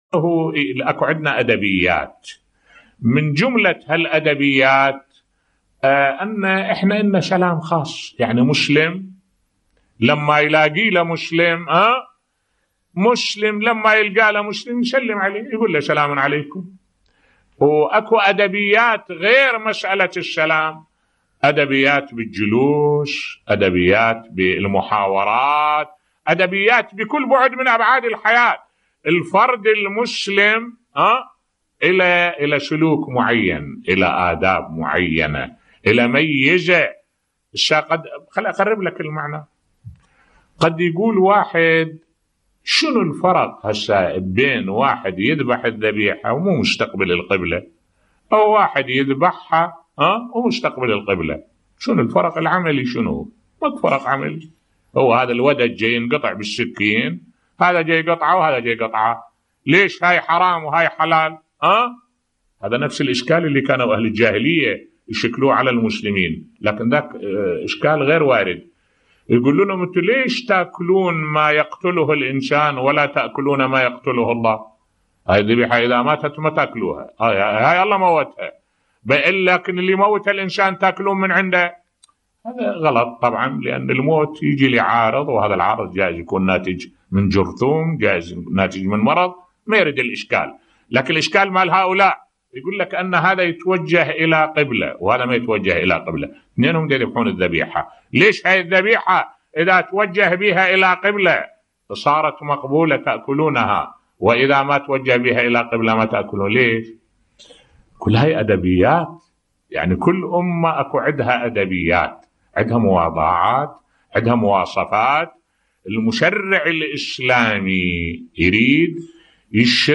ملف صوتی لماذا يشترط استقبال الذبيحة للقبلة حال الذبح بصوت الشيخ الدكتور أحمد الوائلي